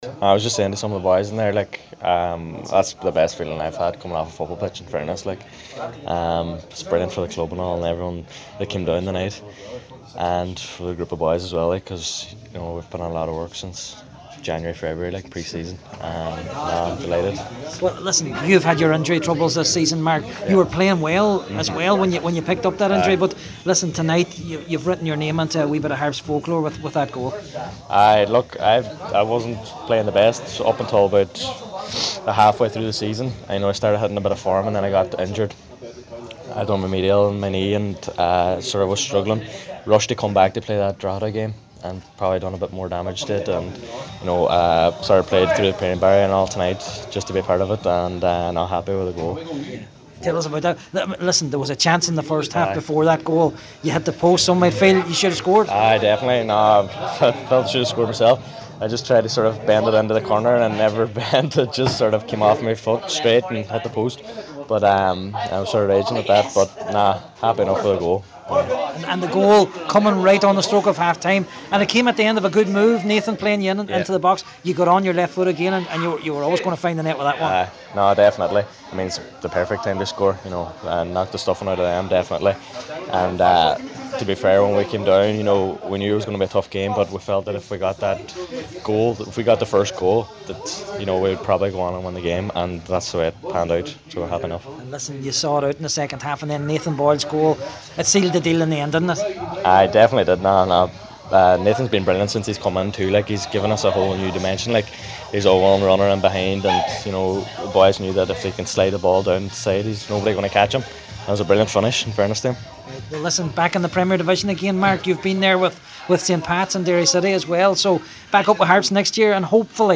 Afterwards he gave his views to Highland Radio.